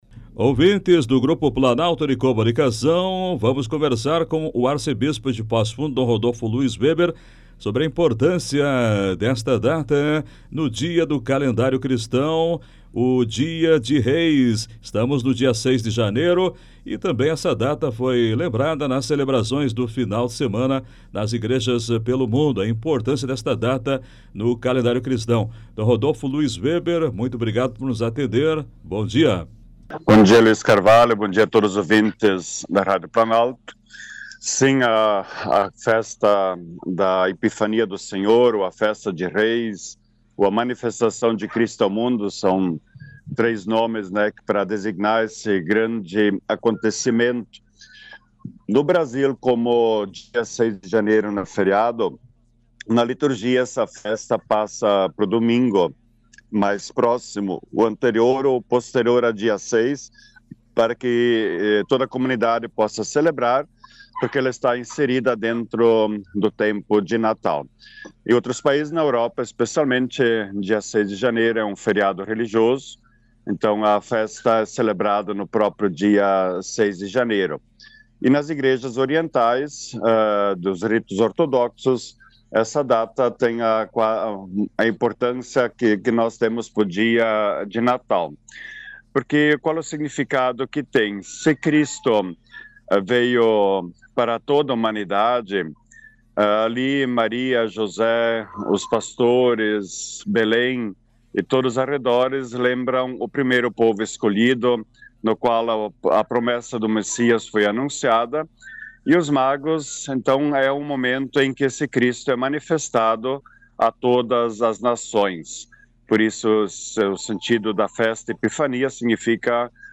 Entrevista: arcebispo dom Rodolfo Weber destaca a importância do Dia de Reis